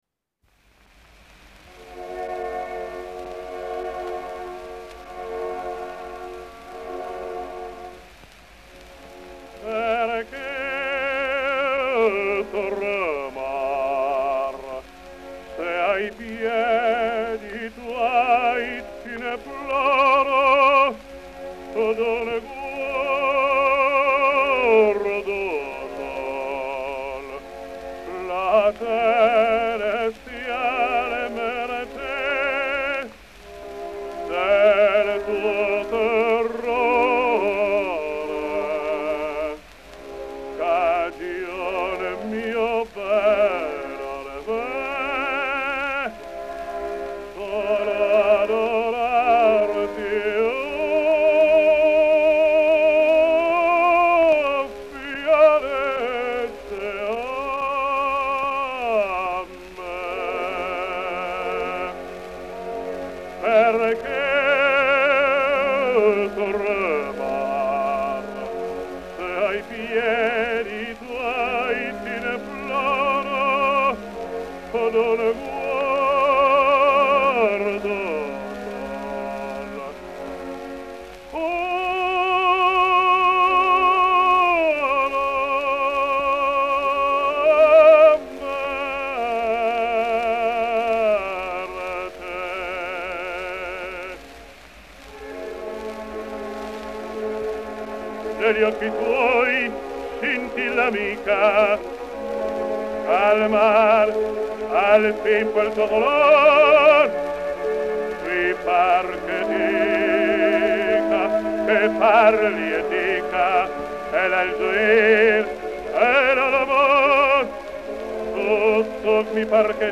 Es existiert davon nur die historische Aufnahme der Arie "Perche tremar?" ('Warum zitterte ich?') des Baritons Mattia Battistini (1856-1928), die man sich aber auch von einer Altstimme gesungen vorstellen kann.
Die Arie 'Perche tremar?' aus der Oper Zampa, gesungen 1906 von Mattia Battistini (Historic Recordings, Mono 89045).